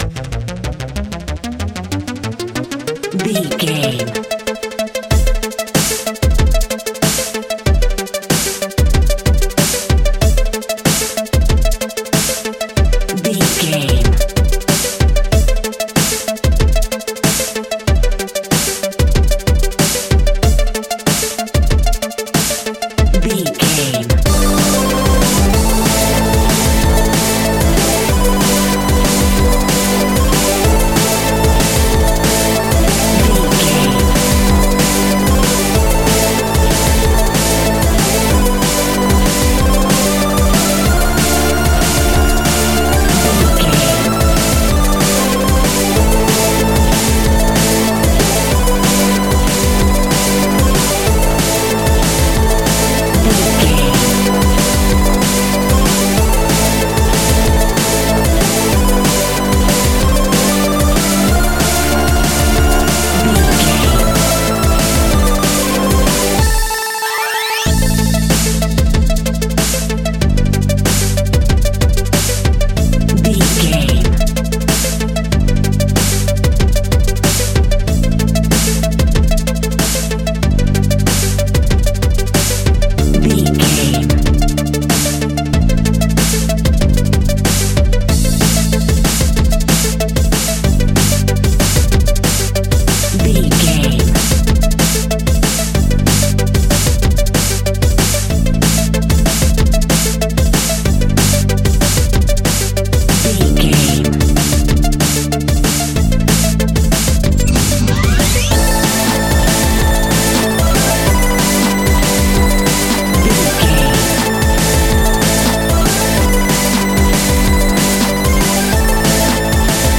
A great piece of royalty free music
Epic / Action
Fast paced
Aeolian/Minor
hard
intense
futuristic
energetic
driving
dark
aggressive
drum machine
break beat
electronic
sub bass
instrumentals
synth leads
synth bass